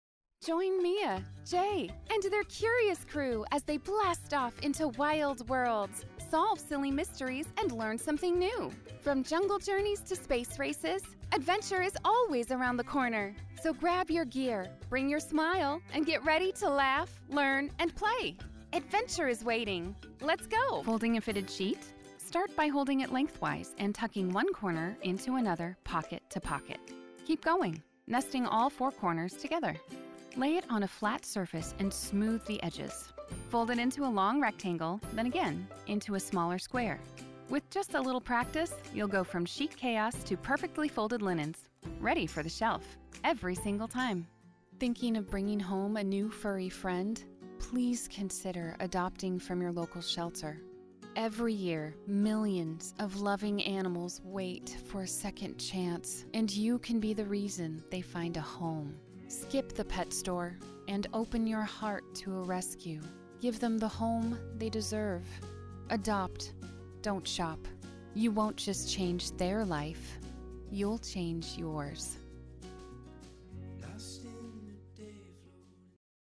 Authentic, Professional voiceovers for brands, videos, and stories that deserve to be heard.
Narration Demo